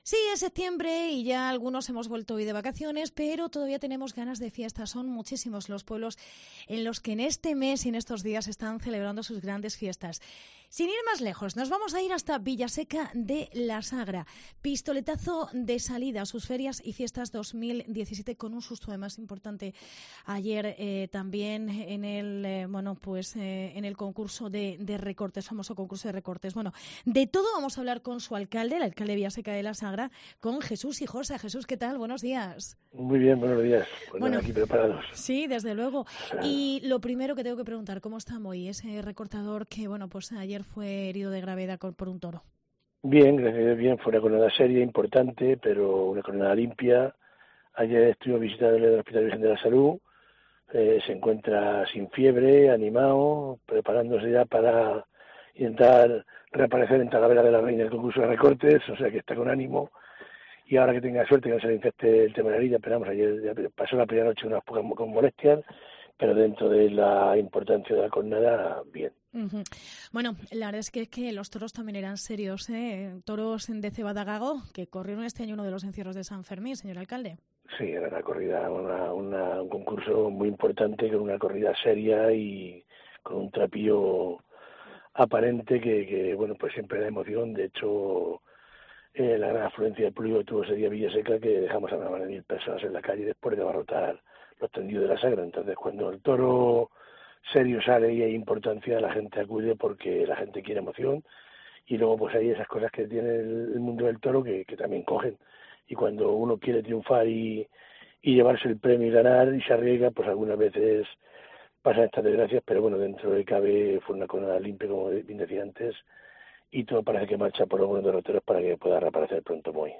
Entrevista Jesús Hijosa. Alcalde de Villaseca de la Sagra